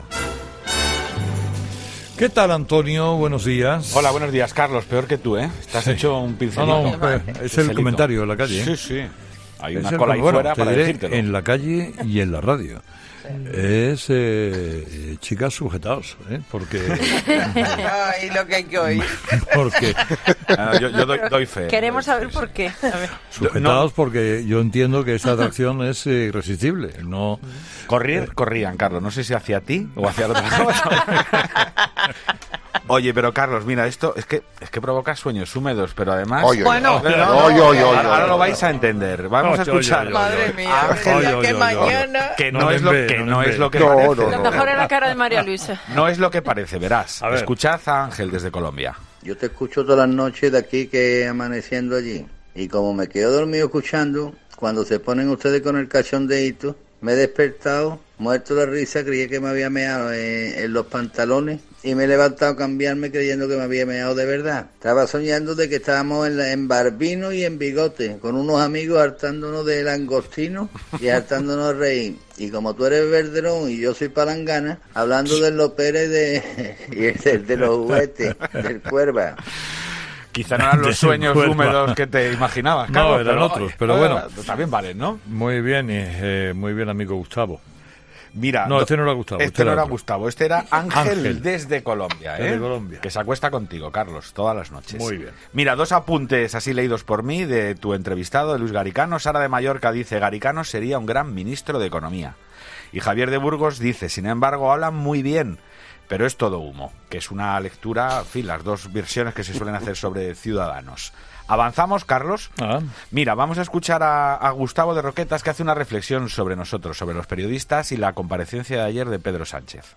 La confesión de un seguidor de Carlos Herrera ha sido la frase estrella de la sección que Herrera cede a sus seguidores para que opinen sobre la actualidad